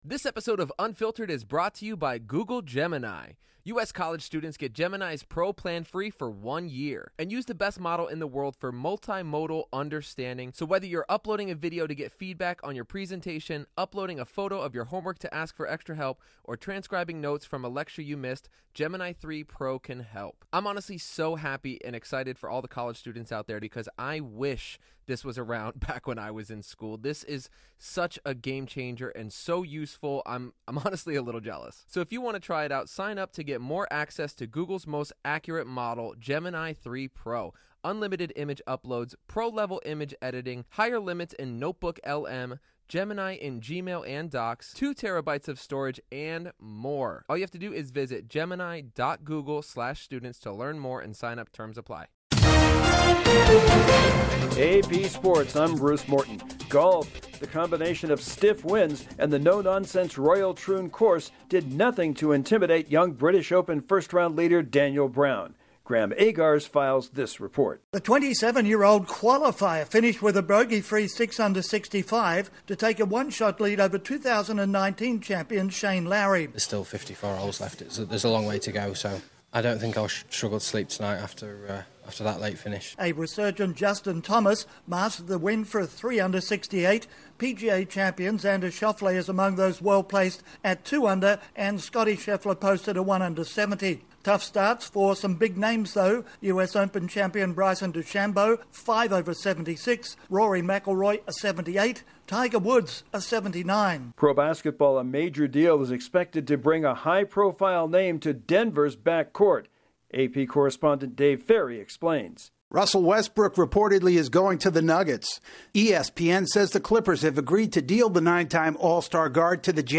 A sizzling first round has a young tour member atop the British Open leaderboard, in the NBA, it looks like a former MVP will be joining the Nuggets, and in baseball, Tampa Bay has a plan to improve its ballpark situation. Correspondent